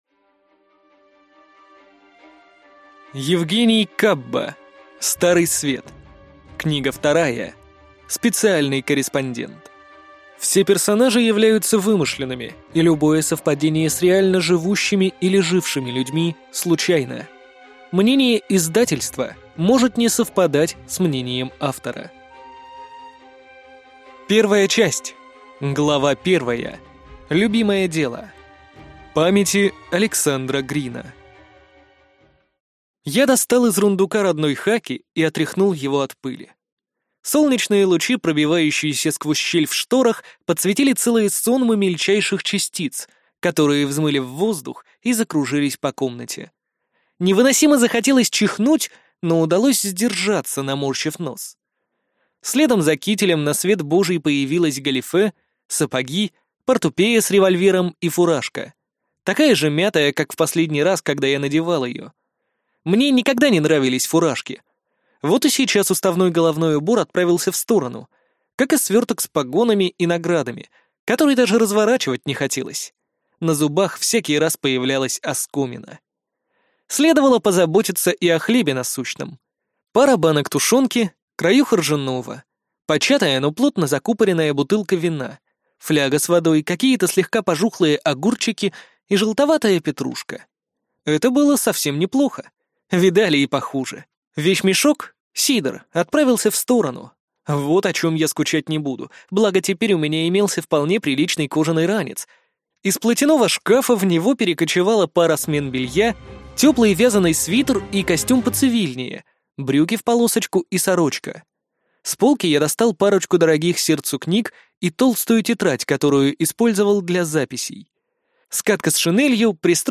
Аудиокнига Старый Свет. Книга 2. Специальный корреспондент | Библиотека аудиокниг